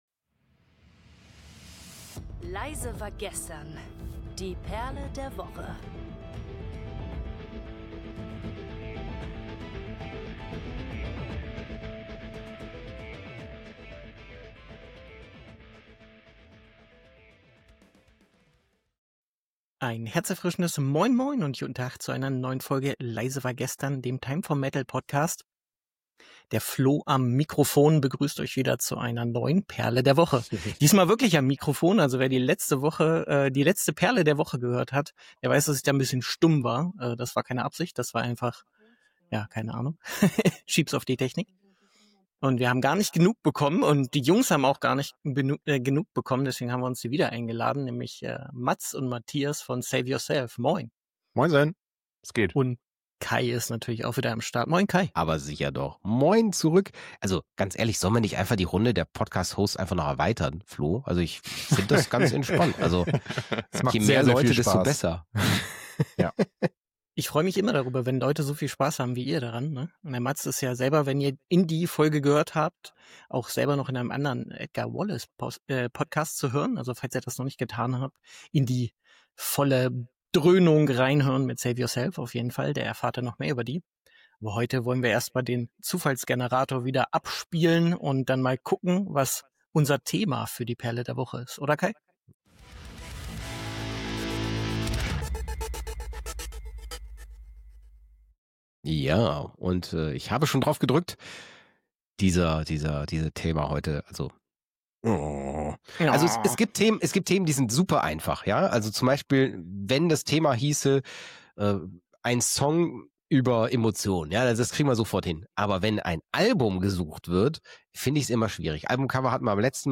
07:10 – Musikabschluss 🎵